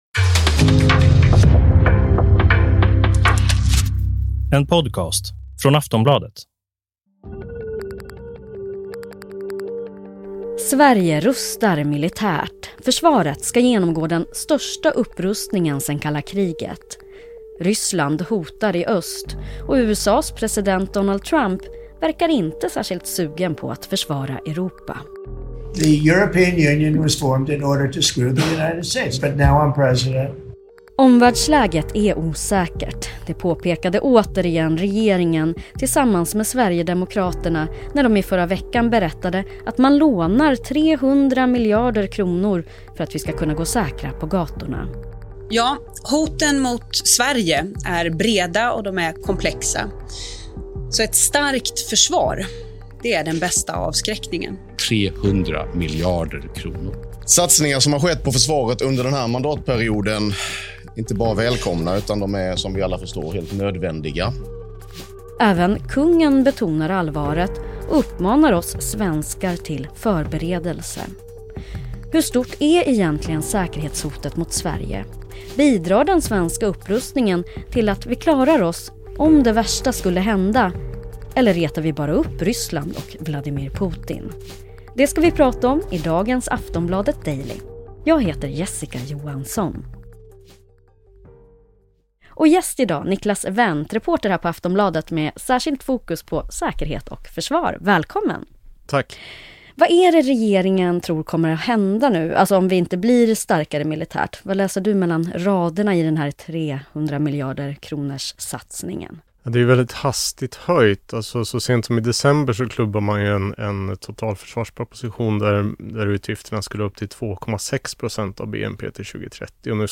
Klipp från: Reuters, Aftonbladet.